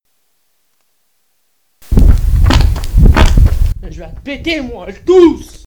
PLAY elle tousse 1
elle-tousse.mp3